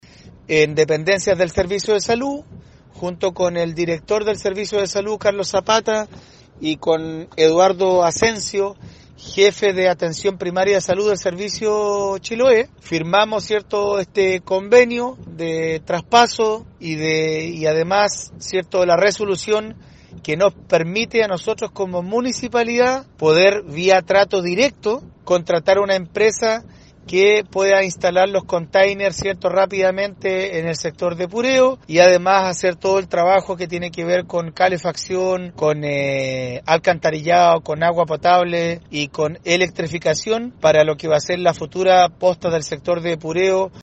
Marcos Vargas, alcalde de Queilen, destacó la importancia del documento.